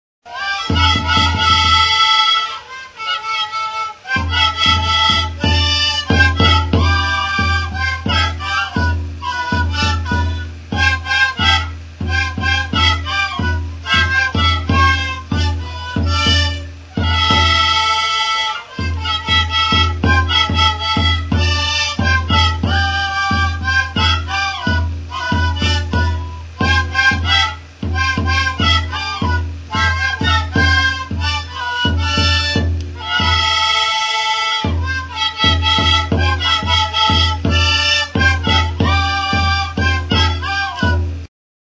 Aerophones -> Flutes -> Fipple flutes (two-handed) + kena
AMERICA -> BOLIVIA
Bi eskuko flauta zuzena da. 6 zulo ditu aurrekaldean.